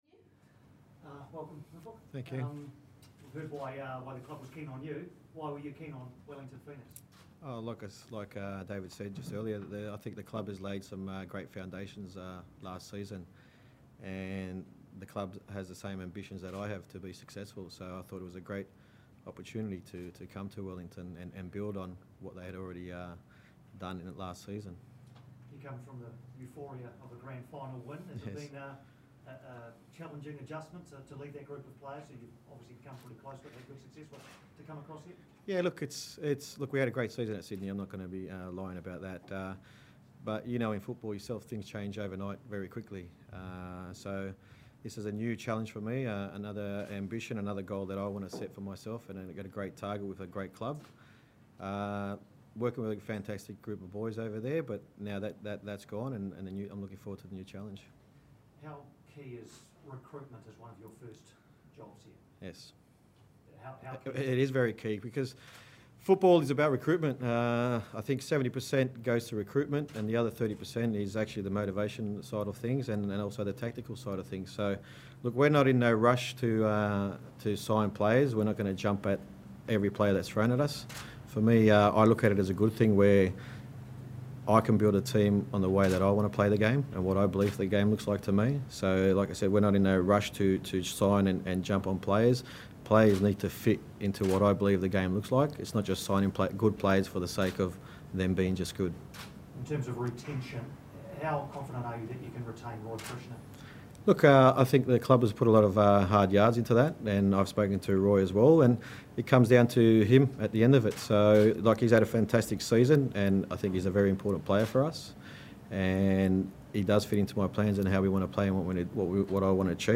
Ufuk Talay Press Conference